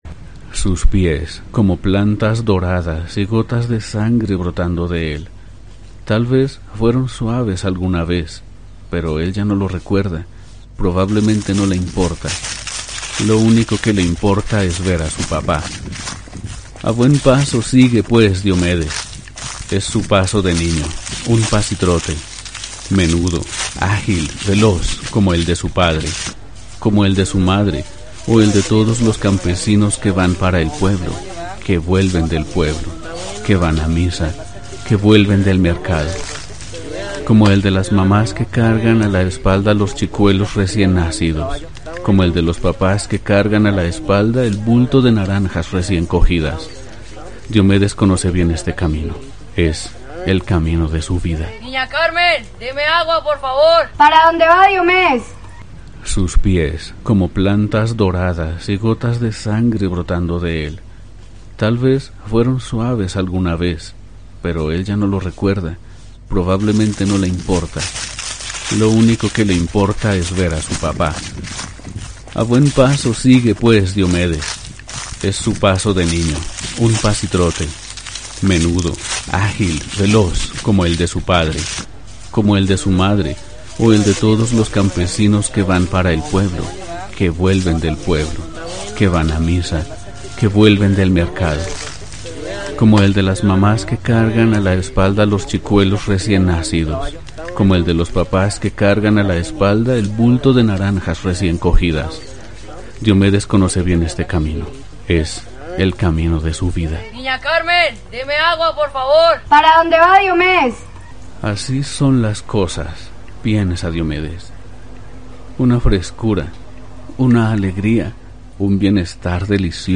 Voice over para e-Learning y documentales, estilo Nat-Geo.
kolumbianisch
Sprechprobe: Sonstiges (Muttersprache):